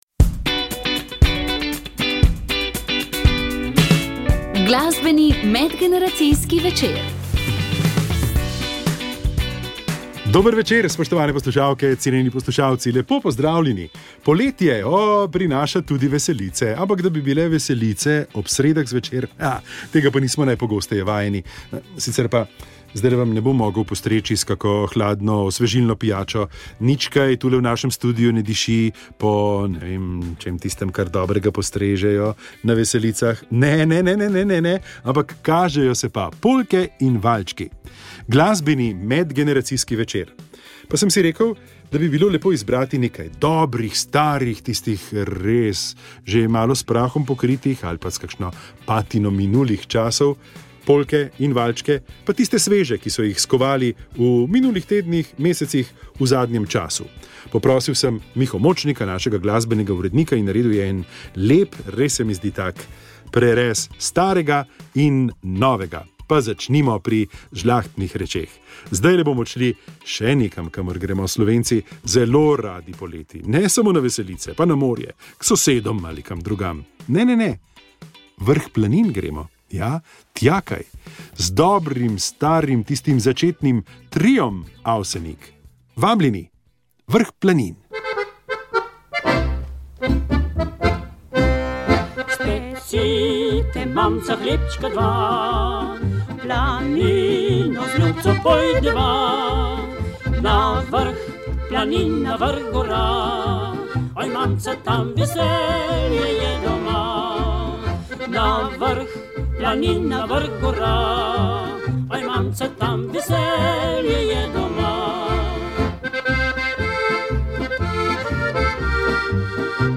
Pogovor o